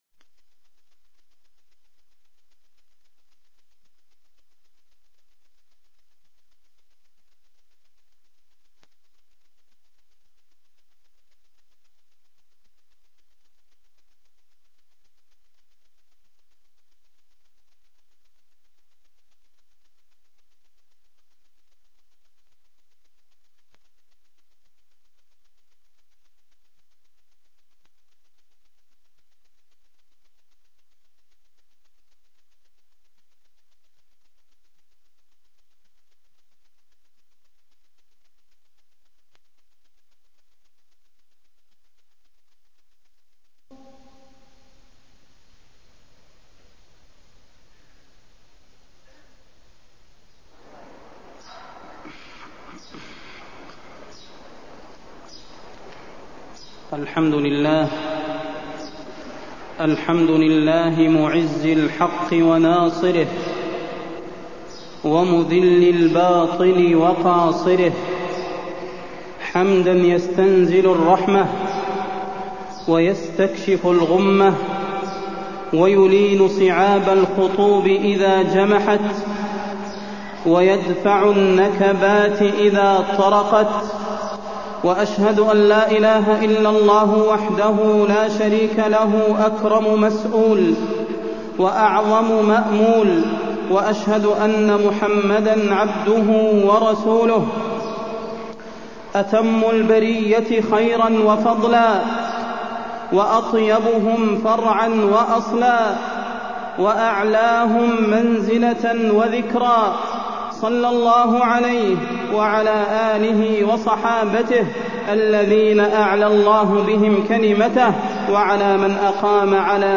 تاريخ النشر ٦ ربيع الثاني ١٤٢٤ هـ المكان: المسجد النبوي الشيخ: فضيلة الشيخ د. صلاح بن محمد البدير فضيلة الشيخ د. صلاح بن محمد البدير الحملة على الاسلام والمسلمين